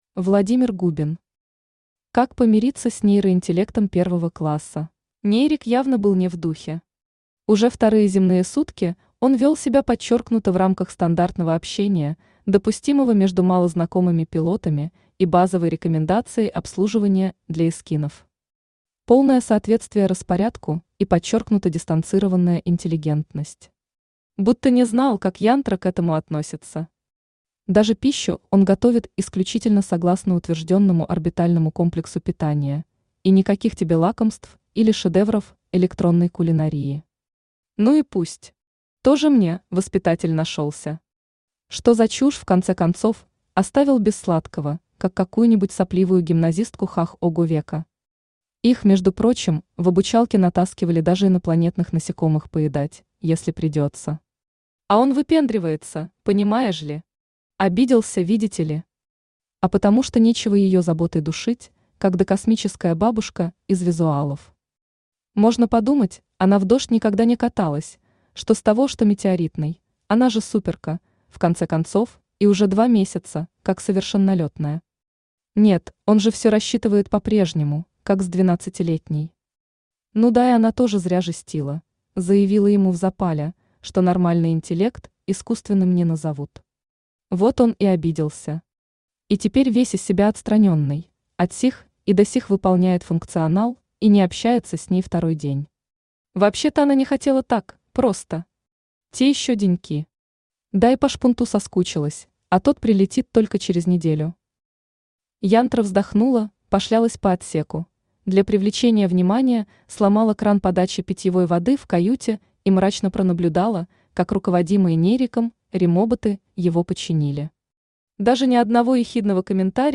Aудиокнига Как помириться с нейроинтеллектом 1-ого класса Автор Владимир Губин Читает аудиокнигу Авточтец ЛитРес.